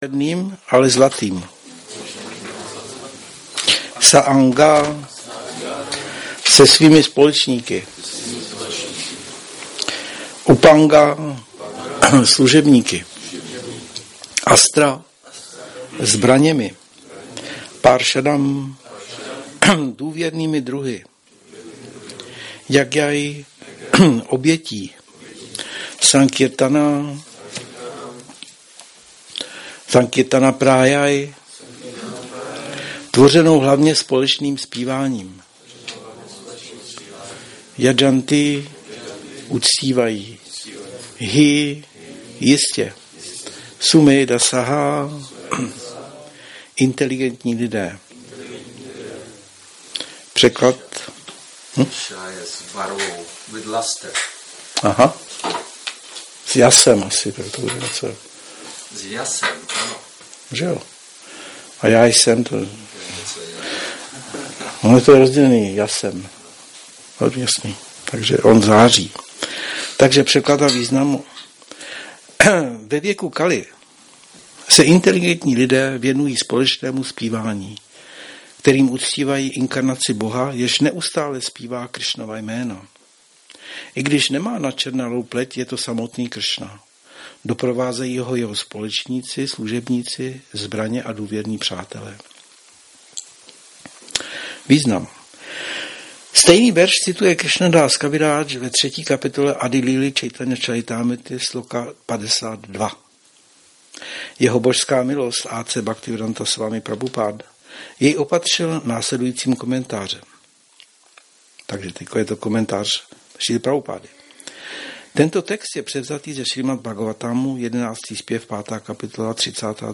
Šrí Šrí Nitái Navadvípačandra mandir
Přednáška SB-11.5.32